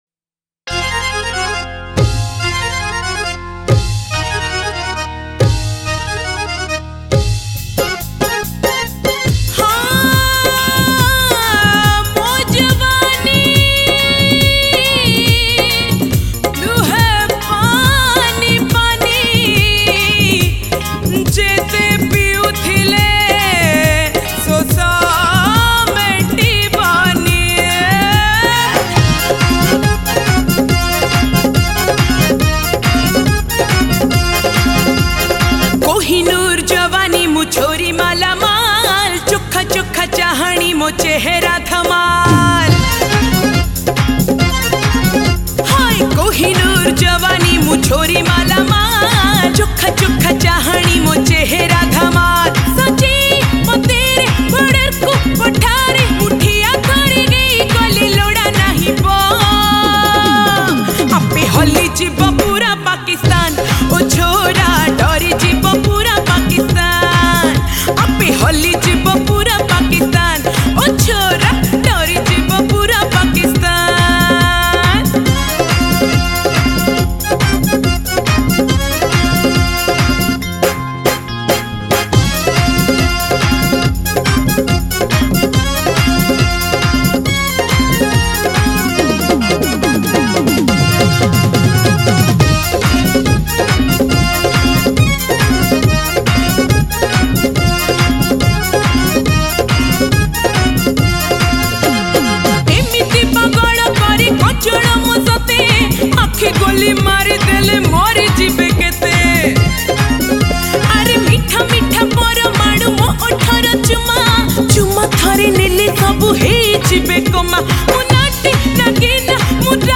Odia Jatra Song